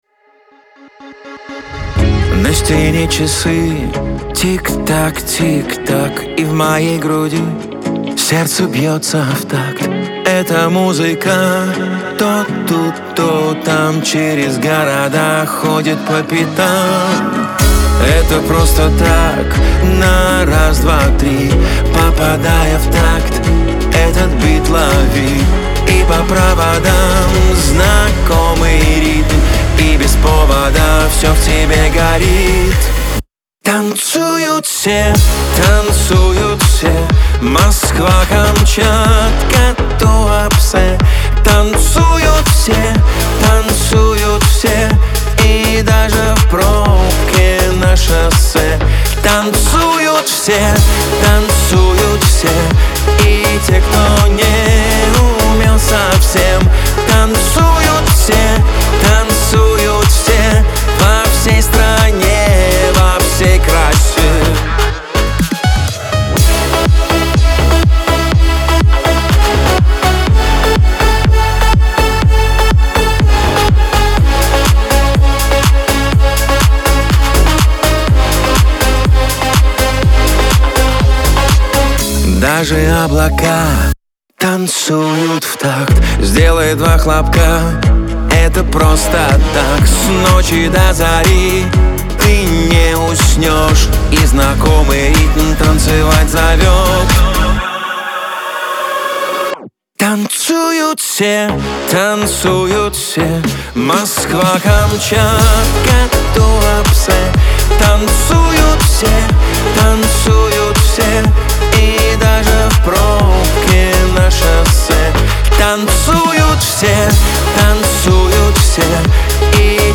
диско , эстрада , танцы
Веселая музыка , pop , танцевальная музыка